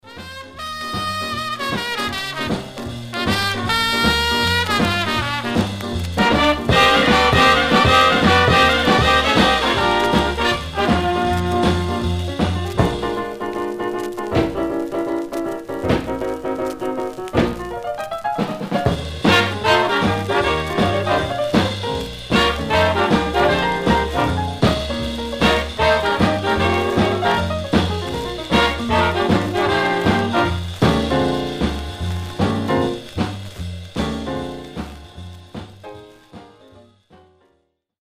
Stereo/mono Mono
Jazz (Also Contains Latin Jazz)